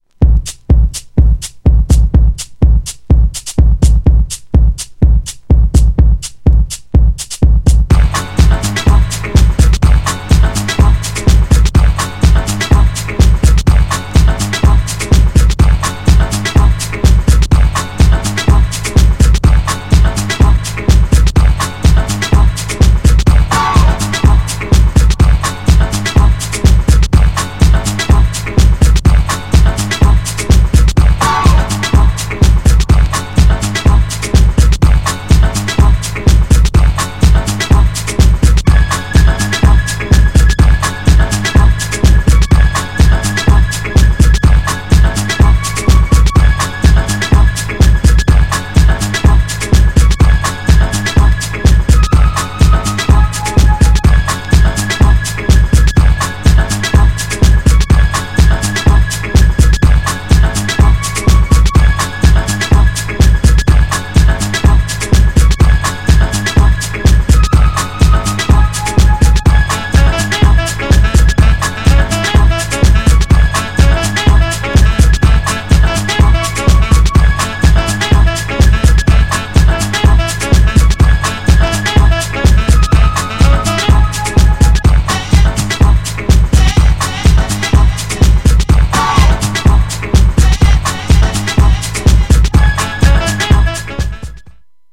GENRE House
BPM 111〜115BPM